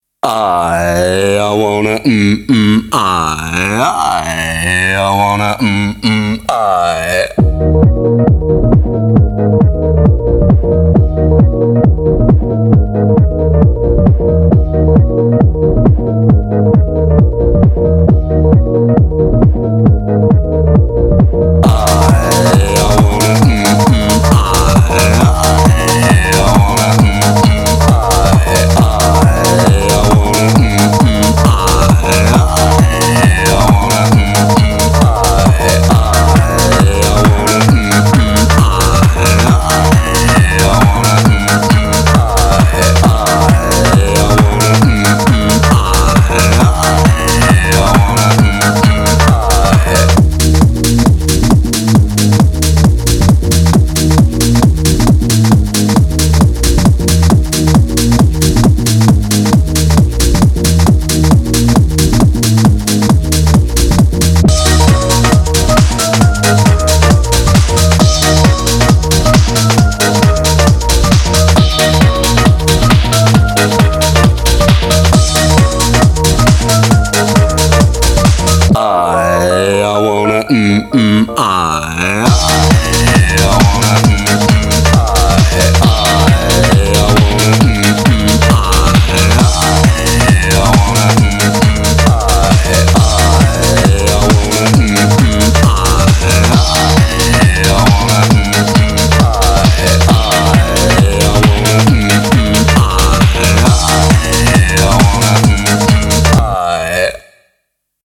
BPM135
Audio QualityPerfect (High Quality)
Comments[DISCO HOUSE]